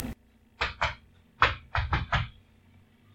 描述：Los sonidos grabados de los pasosdelniñoprincipaldel video